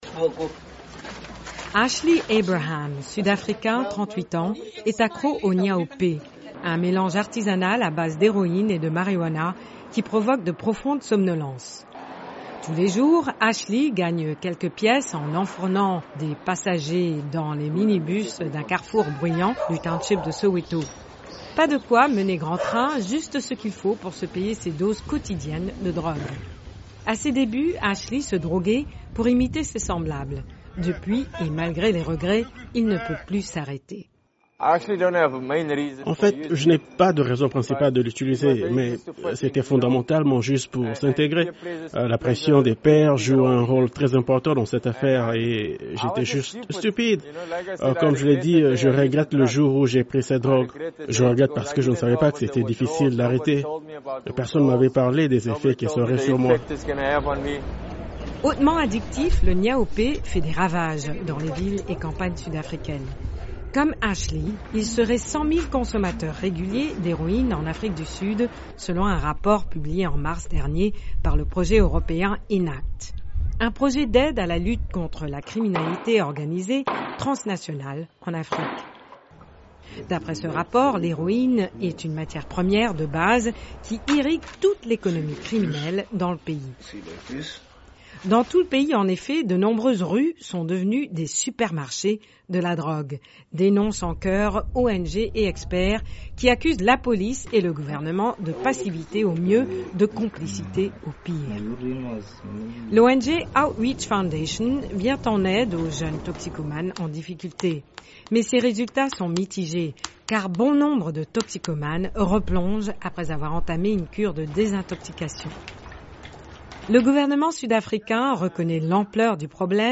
En Afrique du sud, la toxicomanie devient si inquiétant dans le pays que le président Cyril Ramaphosa a dû faire en juin une déclaration sur ce fléau. Reportage à Soweto, à Johannesburg-même et dans la ville du Cap.